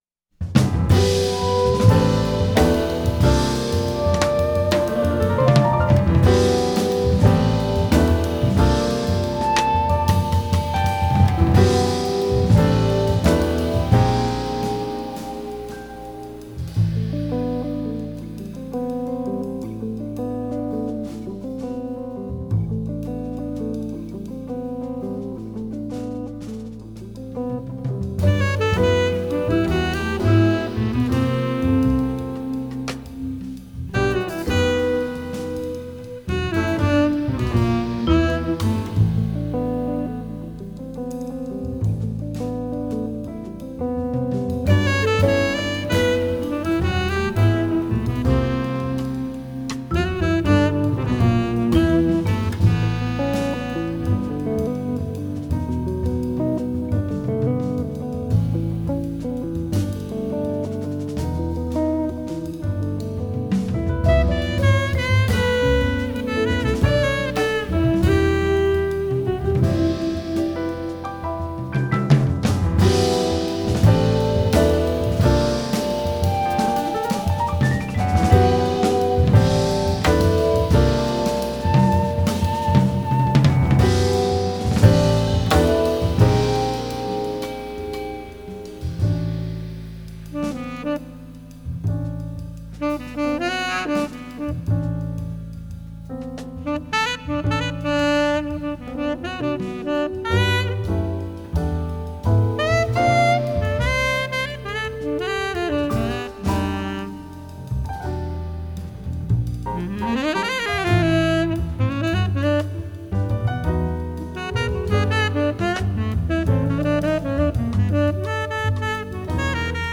One a modern jazz composition, the other a sultry art song: